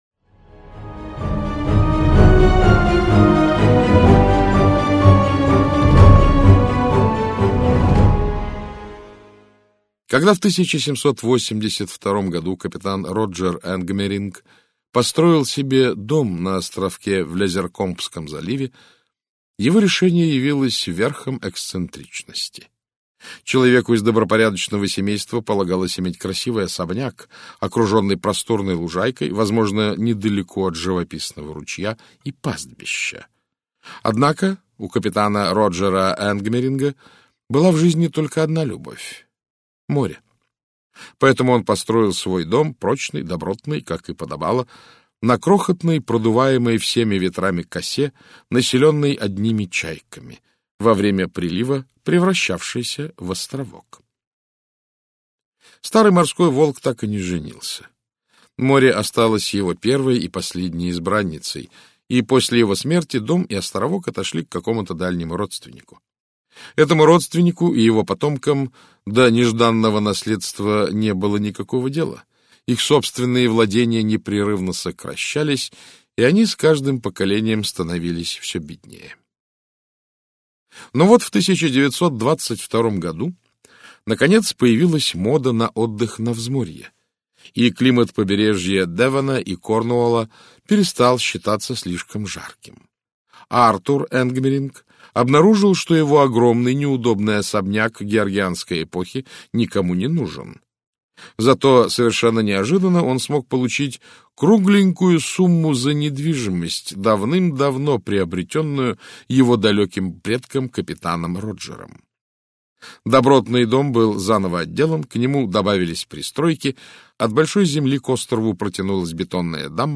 Аудиокнига Зло под солнцем - купить, скачать и слушать онлайн | КнигоПоиск